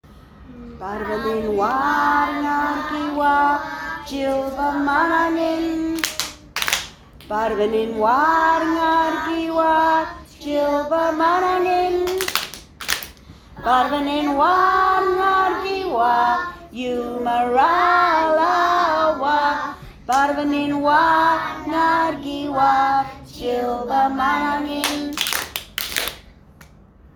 The vocabulary focus has been on reinforcing their learning of the eight Woiwurrung body parts nouns and the accompanying ‘Heads, Shoulders..’ song. Students are also learning a new song: ‘If You’re Happy and You Know It’.